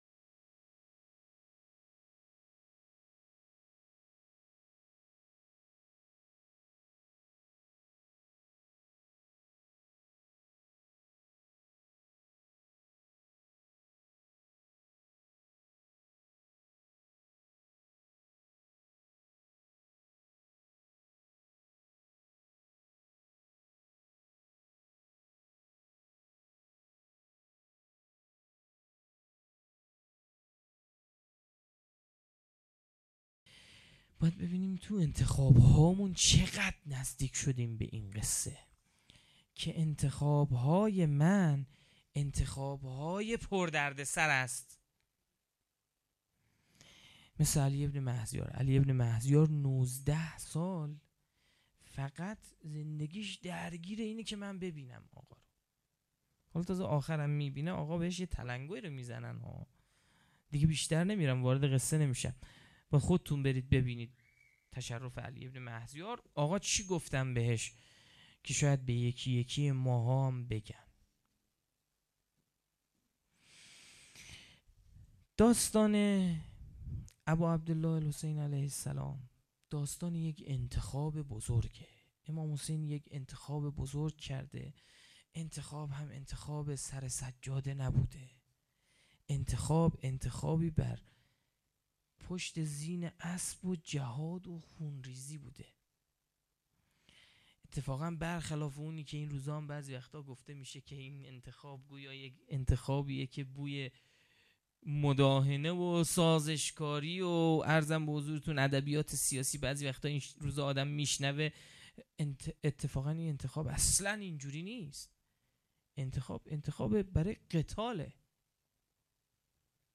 سخنرانی شب 1.mp3
سخنرانی-شب-1.mp3